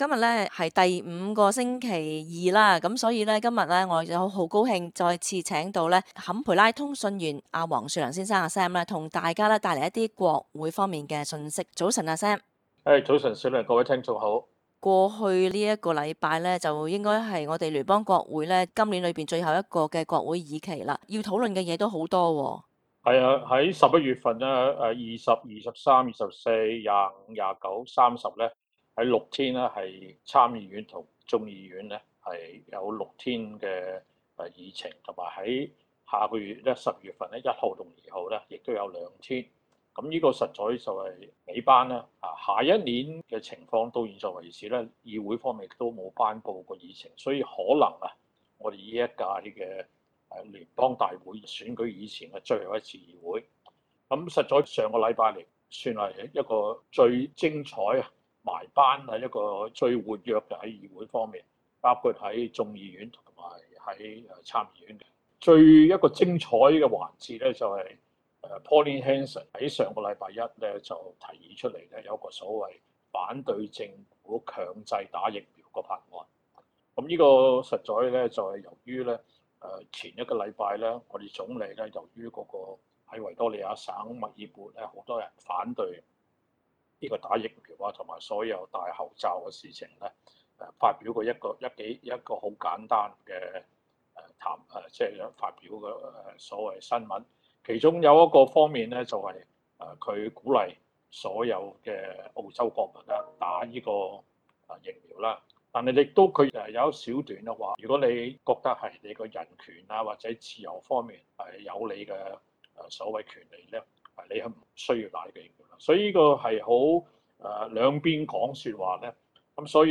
國會通訊